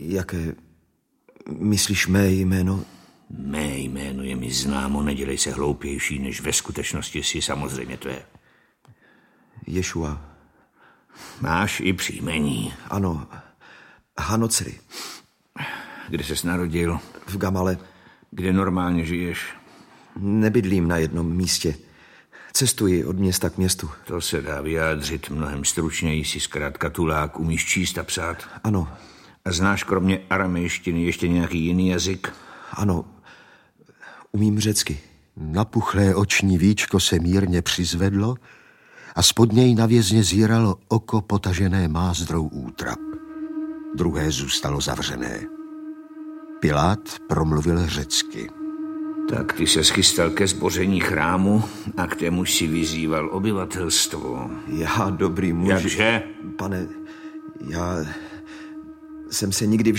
Audiobook
Read: Lukáš Hlavica